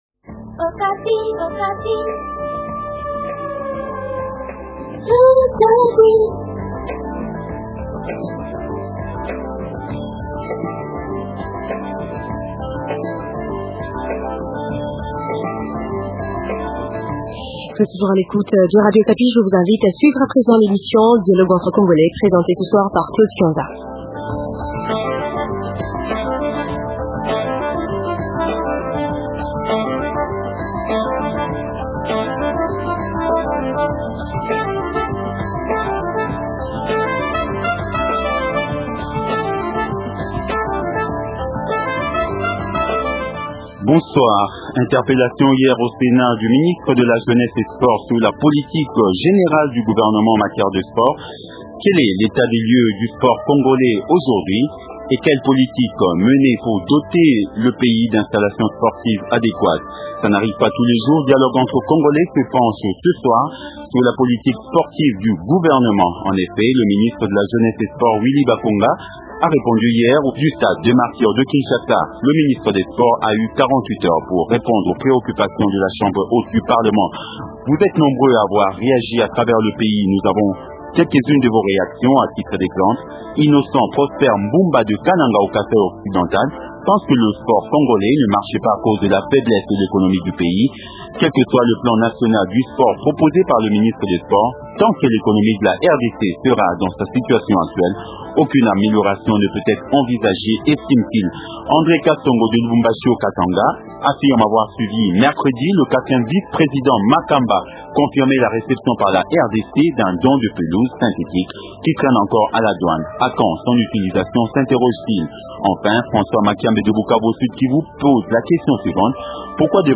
Interpellation au sénat du ministre de la jeunesse et sport sur la politique générale du gouvernement en matière de Sport.
La Plénière du Sénat du mercredi 23 avril 2008 s’est penché sur la question orale avec débat adressée au ministre de la Jeunesse, des Sports et Loisirs. L’auteur de la question, la sénatrice Eve Bazaiba voulait s’enquérir sur la menace de suspension du stade des Martyrs de Kinshasa par la Fifa et la politique générale du gouvernement en matière des sports en RDC.